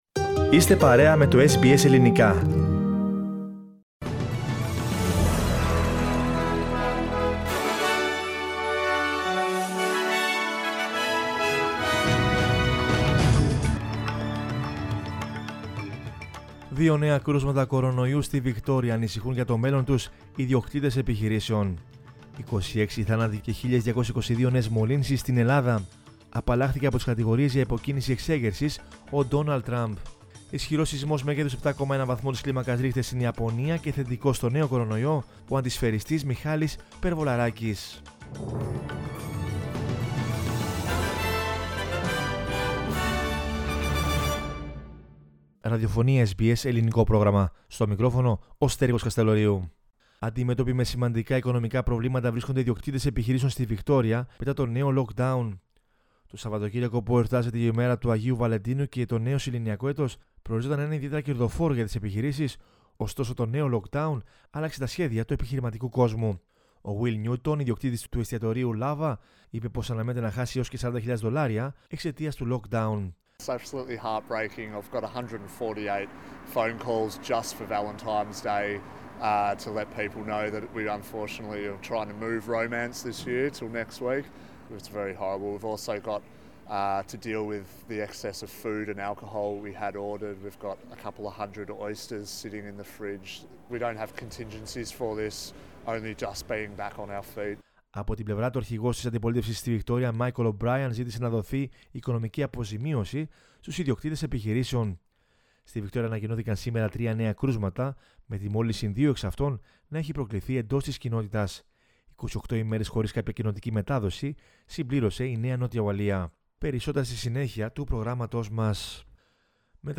News in Greek from Australia, Greece, Cyprus and the world is the news bulletin of Sunday 14 February 2021.